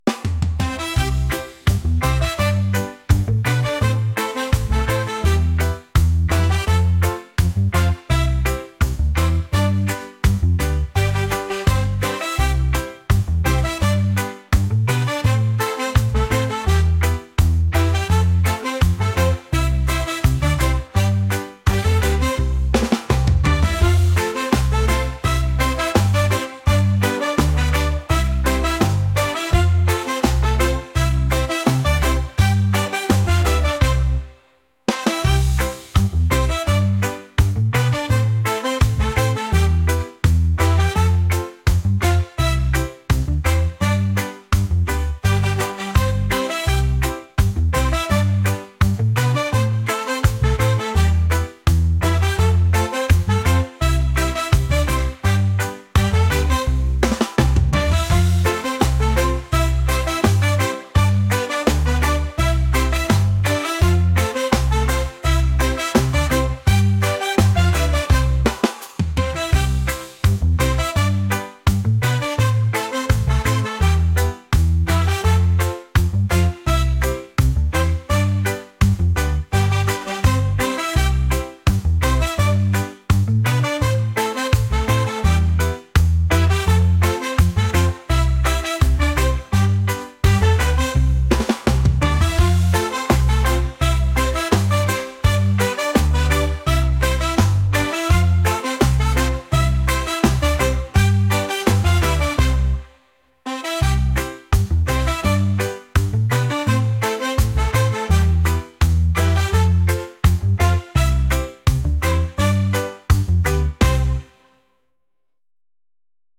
reggae | pop | lounge